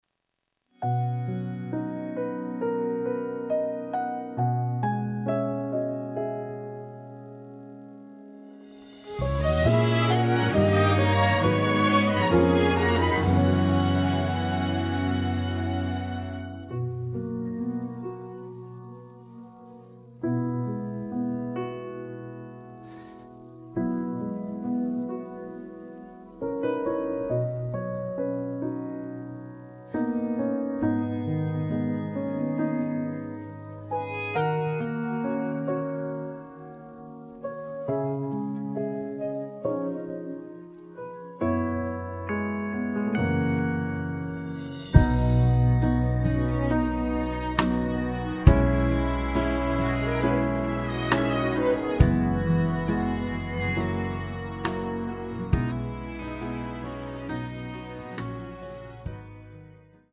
음정 원키 3:45
장르 가요 구분 Voice Cut
Voice Cut MR은 원곡에서 메인보컬만 제거한 버전입니다.